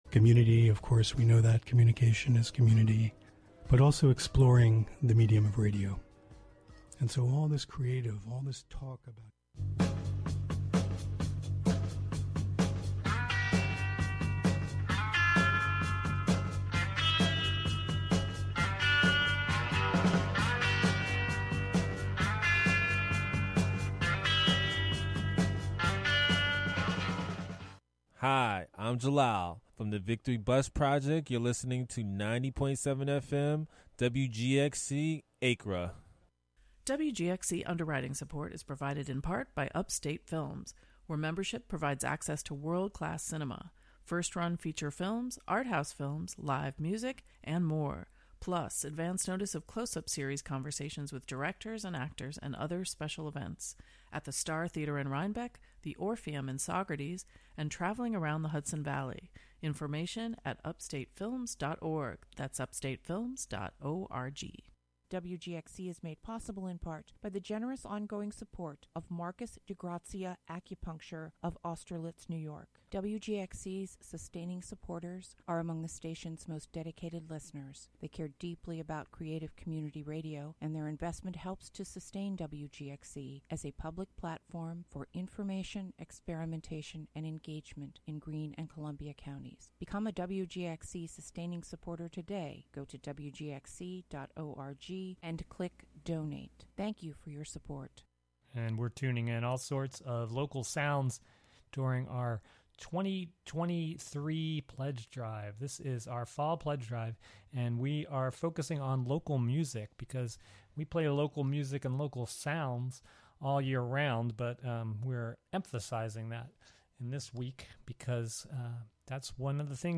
This music mix show